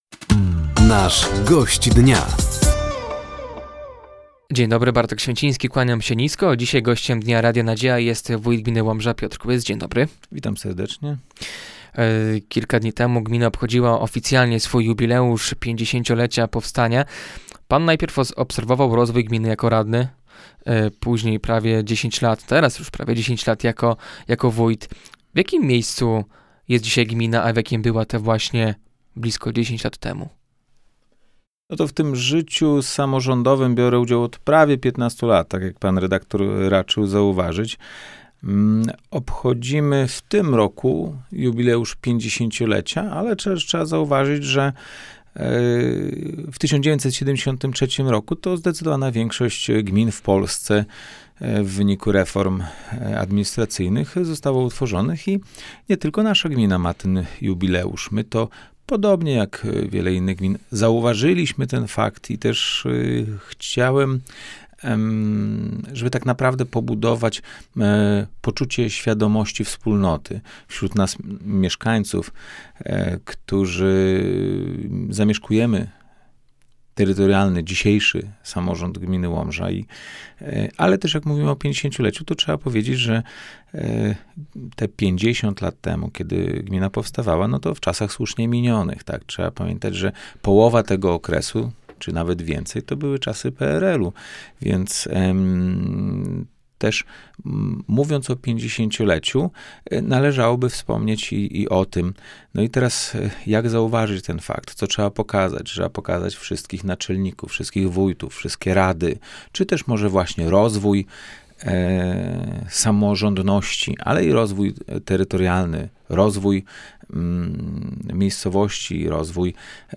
Gościem Dnia Radia Nadzieja był wójt gminy Łomża, Piotr Kłys. Tematem rozmowy było między innymi 50-lecie gminy i potencjalny wpływ Centralnego Portu Komunikacyjnego na rozwój ziemi łomżyńskiej.